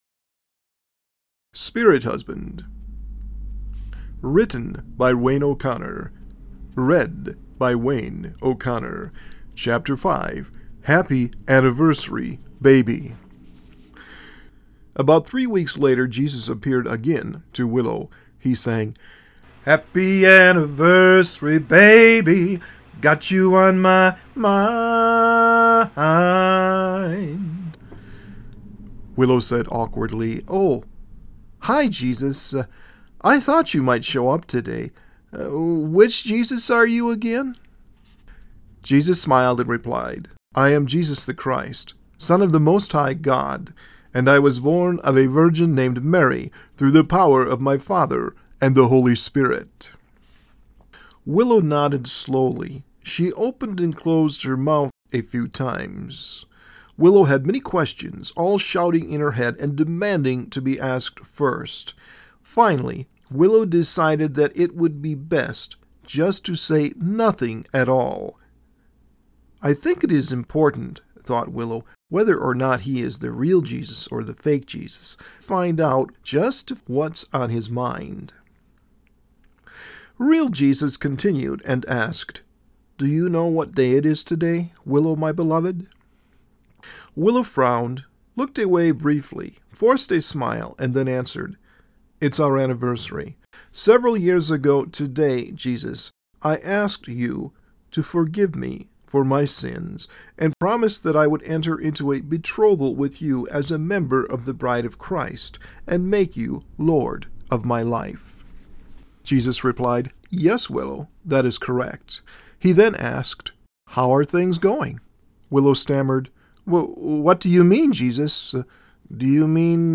Chapter 5 of Spirit Husband Christian Fantasy Audio Book